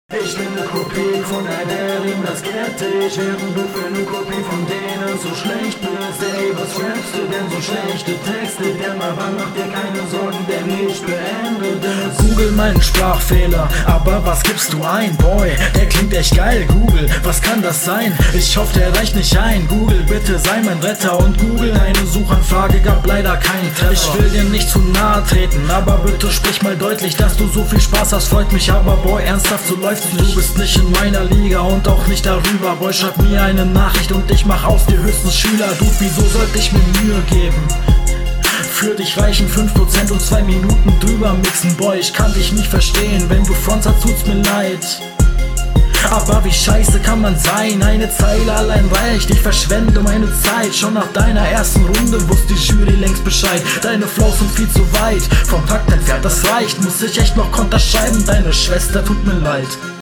Textlich, Stimmlich als auch Flow überlegen.
Jo gyle gerappt, intro war lustig man hört n cut in der Mitte, Lines sind …